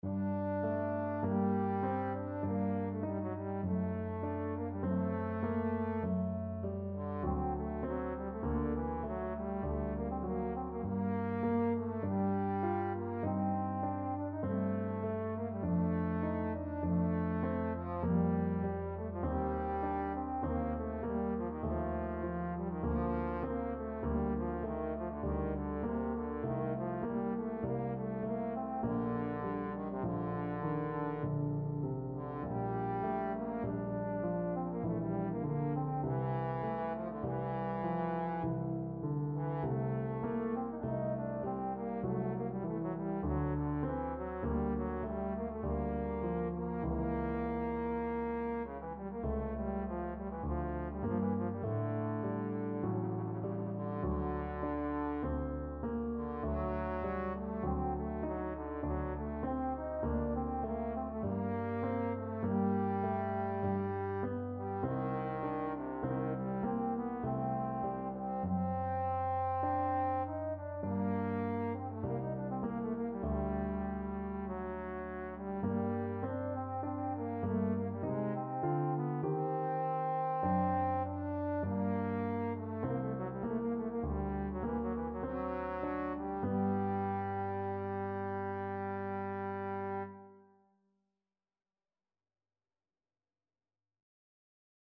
3/4 (View more 3/4 Music)
Largo
Classical (View more Classical Trombone Music)